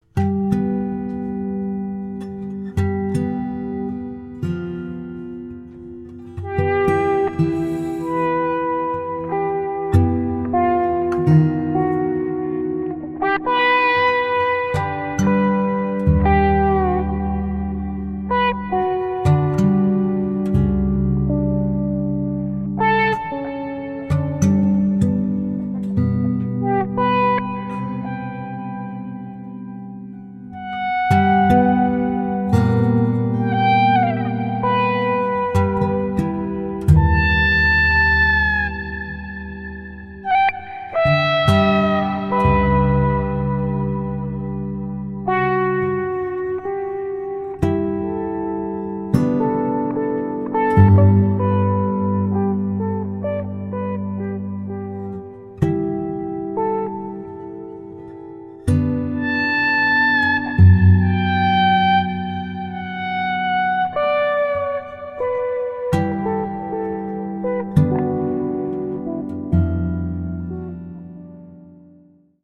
Electric guitar, Samples